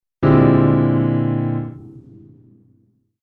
1. B♭(#11)13 als Upper Structure im Latin Jazz:
• Linke Hand: B♭, D, A♭ (B♭7-Basis)
• Rechte Hand: C, E, G (C-Dur-Überlagerung)
• Interpretation: B♭7 + C-Dur
latinjazz_am_klaver_Bb11_13.mp3